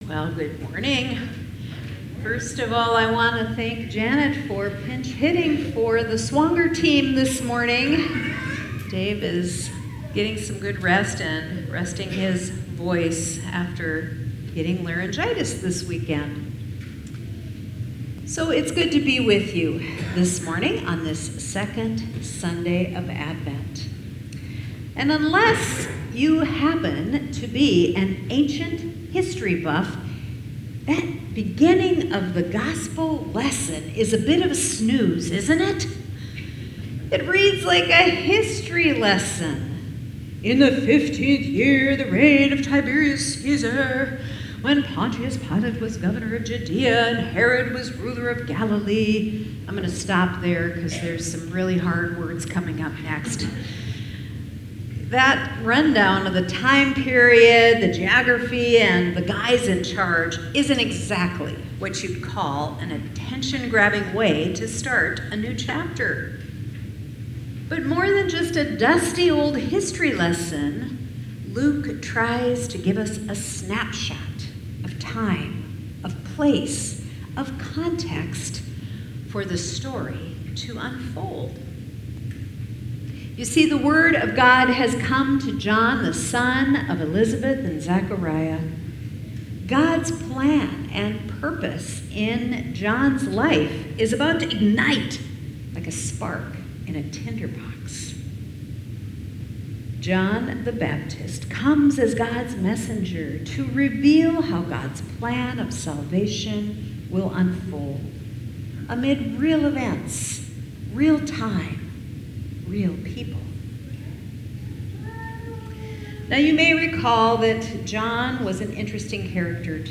Sermons | Moe Lutheran Church
Sermon Notes